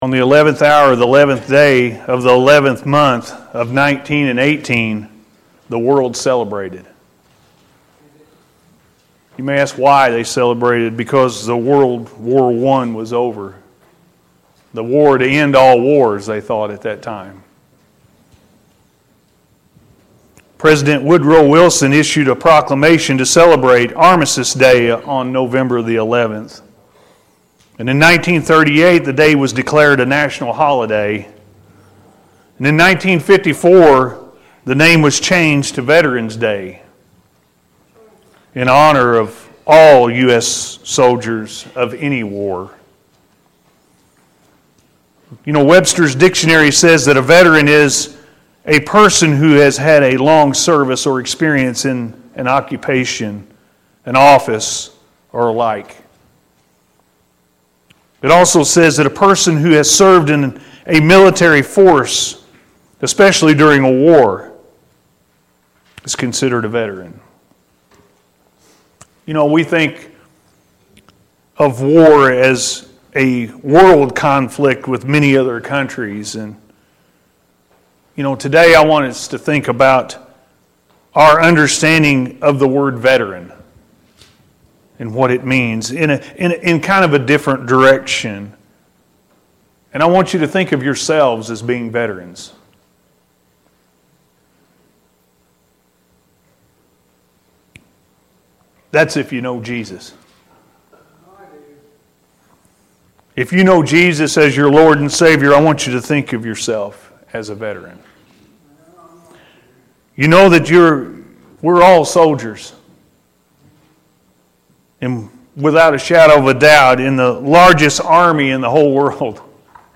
Soldiers In God’s Army-A.M. Service – Anna First Church of the Nazarene